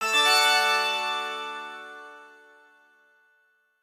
SYNTHPAD021_DISCO_125_A_SC3.wav